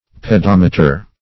Pedometer \Pe*dom"e*ter\, n. [Pedi-, pedo- + -meter: cf. F.